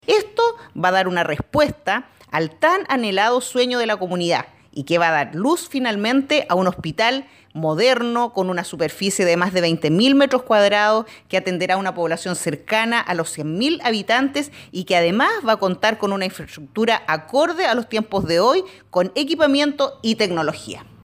Recinto asistencial de mediana complejidad, que permitirá la atención de 100 mil personas de las comunas de Villarrica, Pucón, Curarrehue y Loncoche, respondiendo a la importante demanda de la población en materia de salud, así lo explicó la seremi de esta cartera de Gobierno, Katia Guzmán.